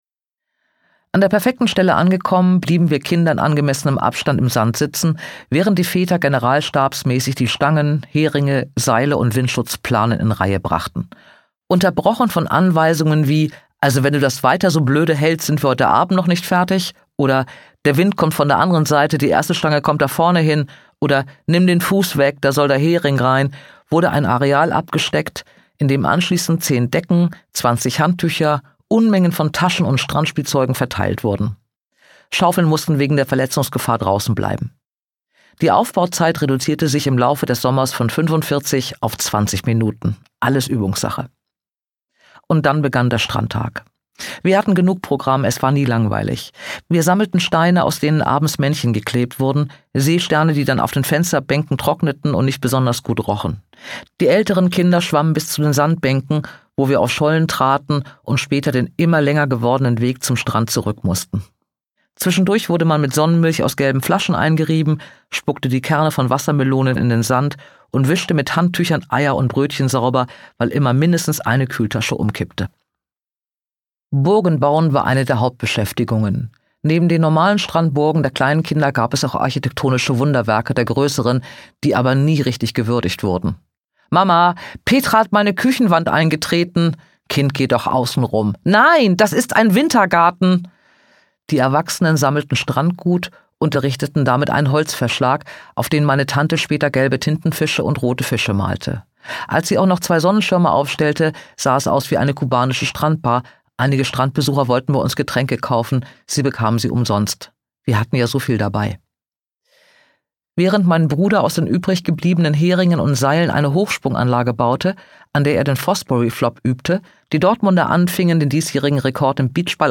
Dora Heldt (Sprecher)